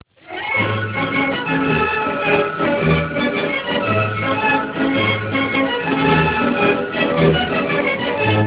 intrascendencias: Tono, politono y sonitono